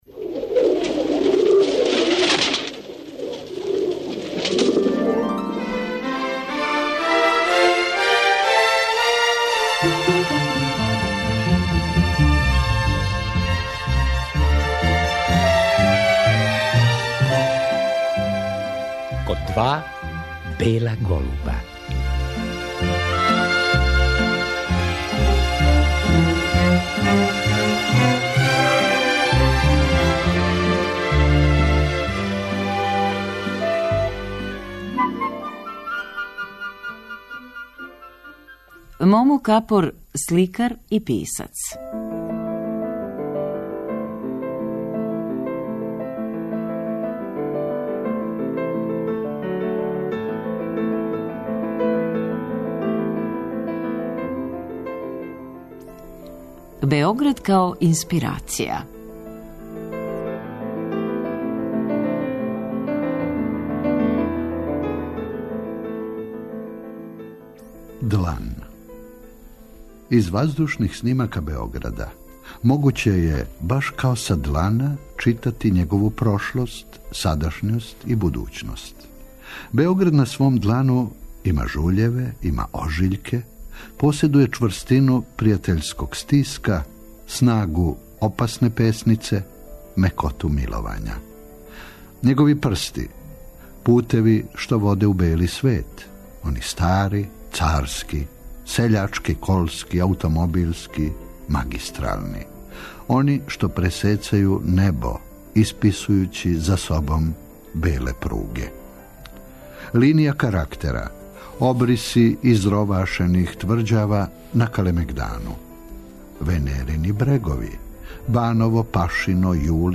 Поводом његове годишњице рођења, 8. априла 1937. године, чућемо још једном тај разговор. Тема је Београд као инспирација.